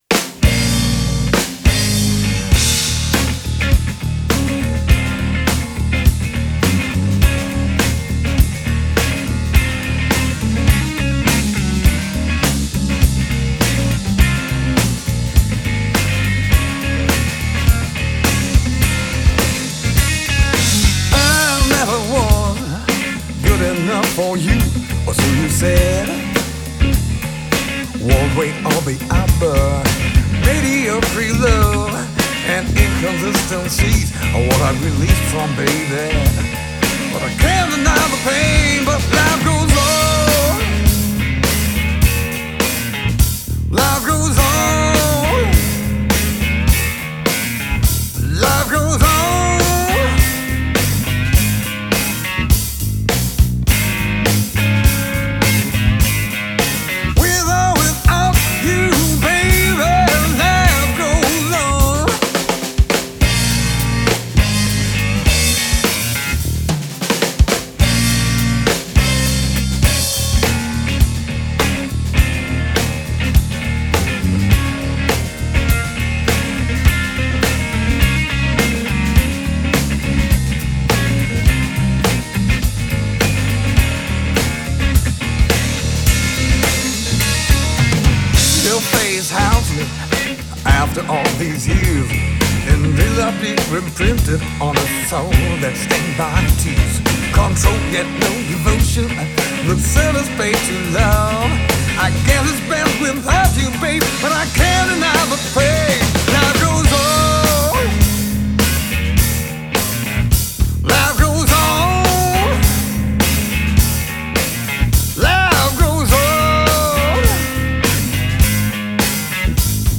Rock&Blues